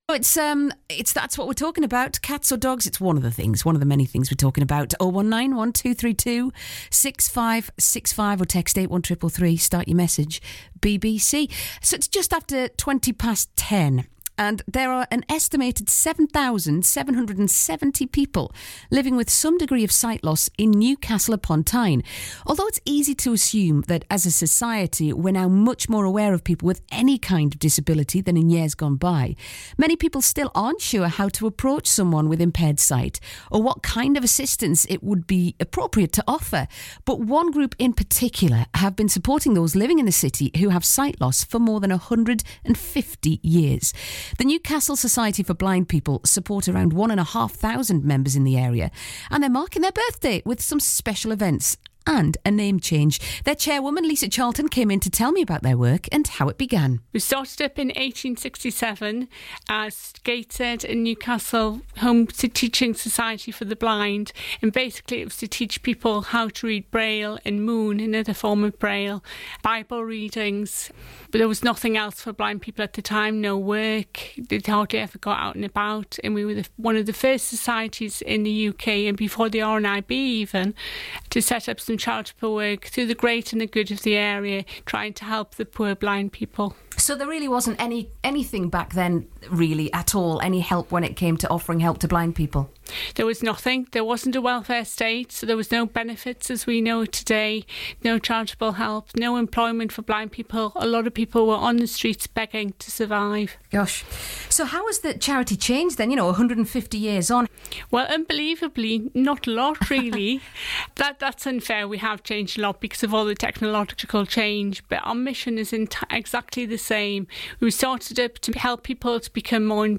Radio-Newcastle-interview-2806medium.mp3